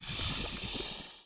Index of /App/sound/monster/misterious_diseased_bow
walk_act_1.wav